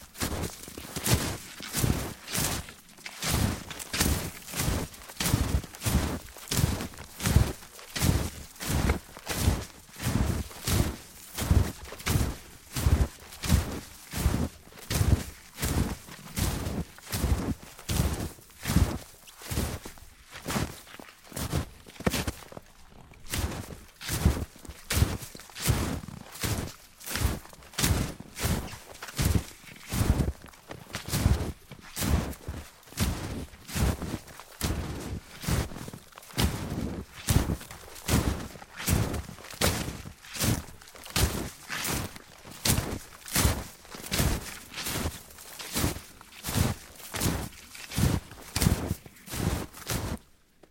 冬天" 脚步声 雪鞋 老木头2 深雪 缓慢 中等速度 软绵绵的 蓬松的 漂亮1
描述：脚步雪鞋老wood2深雪慢中速软脆蓬松nice1.flac
Tag: 脚步 雪鞋 wood2